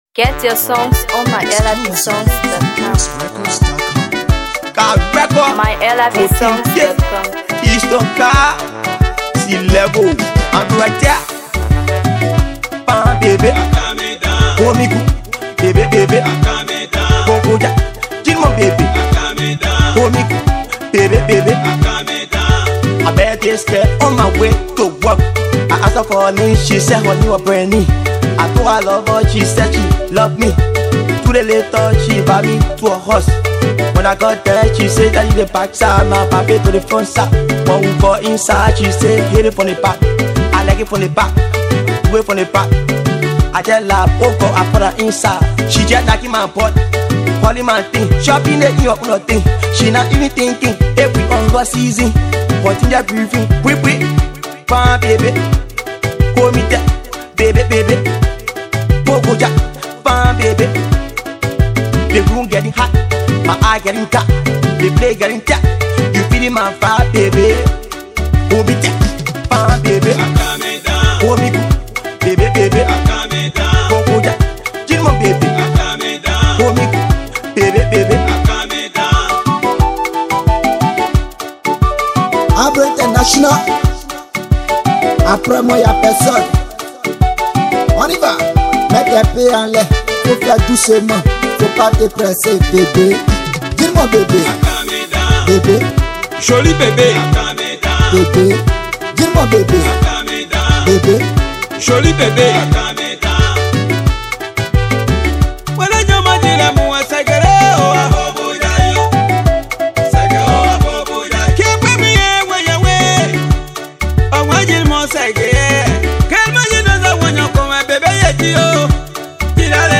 the track infuses vibrant rhythms and captivating melodies
With infectious beats and heartfelt lyrics